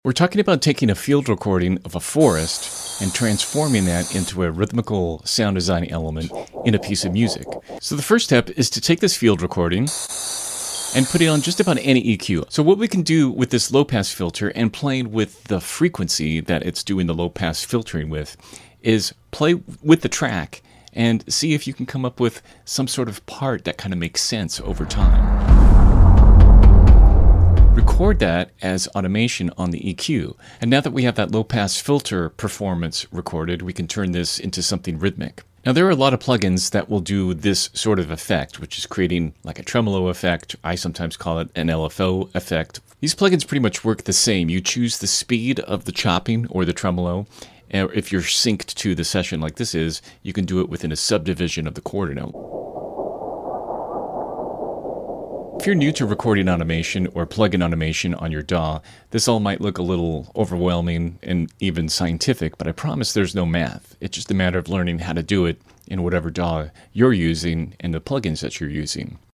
Sound Design Rhythms From A sound effects free download
Sound Design Rhythms From A Forest Recording